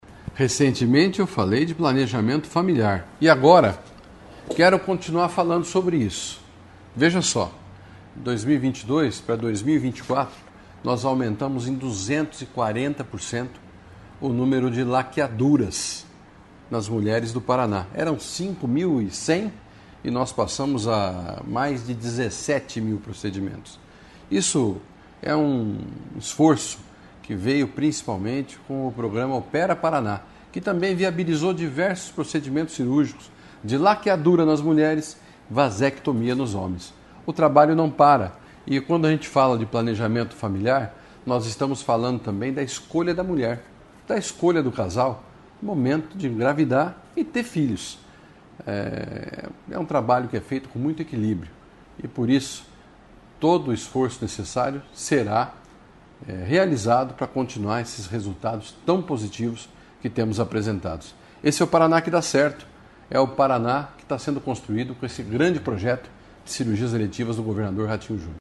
Sonora do secretário Estadual da Saúde, Beto Preto, sobre o aumento no número de laqueaduras e vasectomias no Paraná